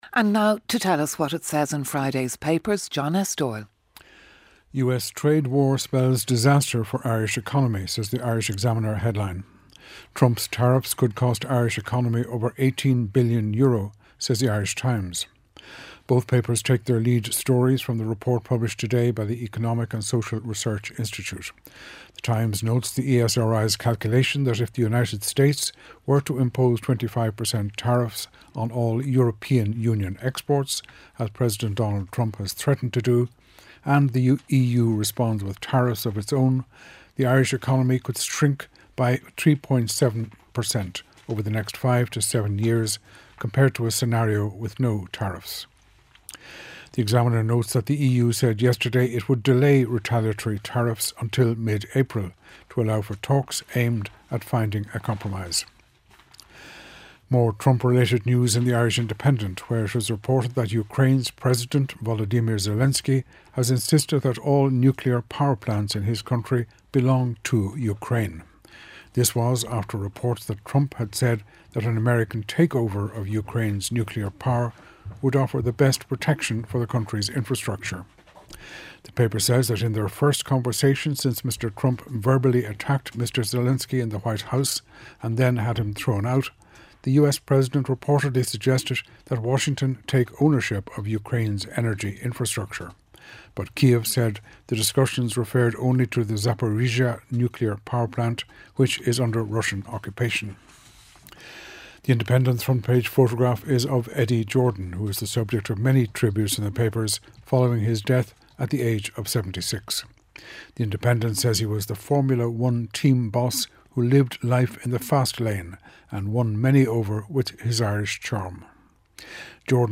8am News Bulletin - 21.03.2025